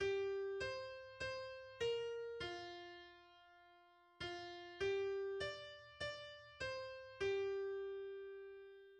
in beeld komt klinkt een vrolijke honky tonk piano en